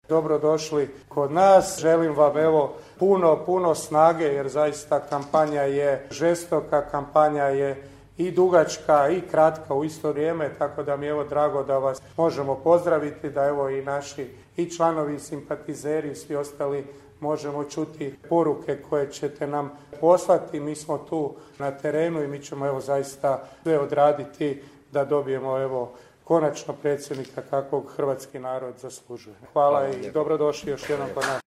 Započeo ju je u Garešnici, gdje se sastao s članovima Gradskog odbora stranke i građanima u Centru za posjetitelje.